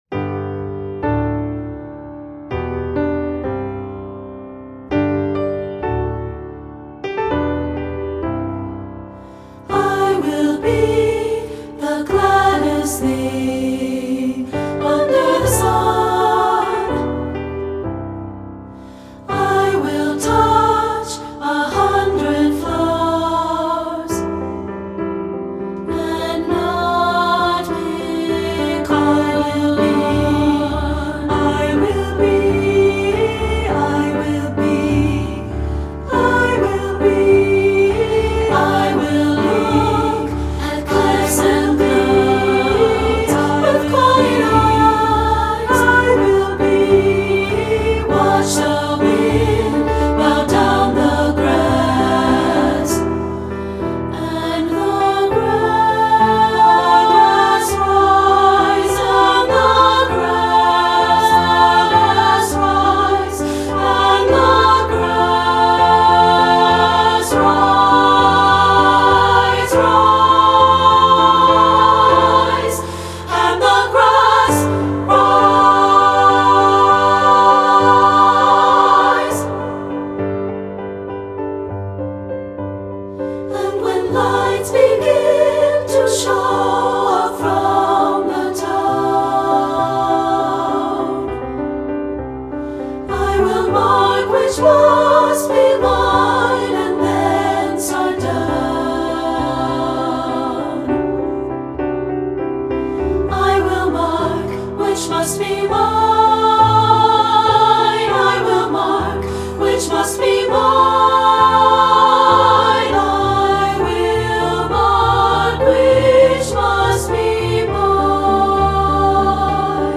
Voicing: SSA Collection